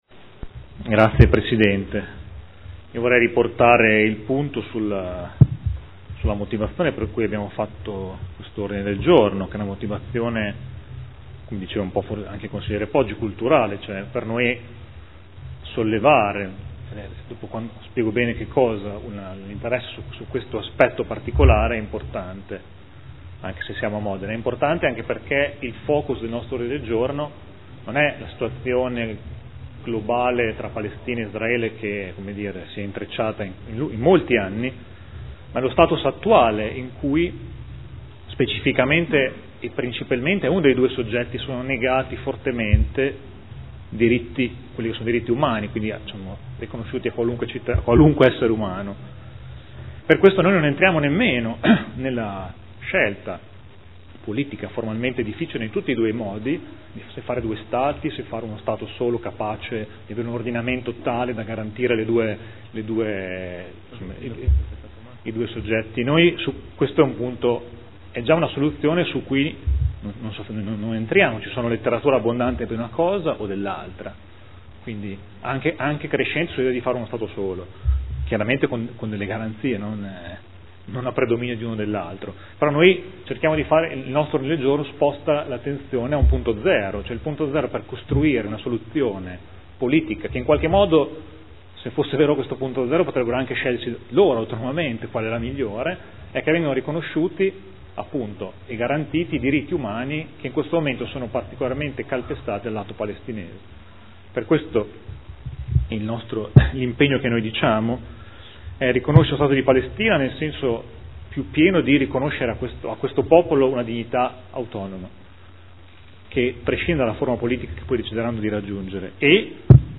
Seduta del 21/05/2015 dibattito mozioni 27236 e 58705 sulla Palestina.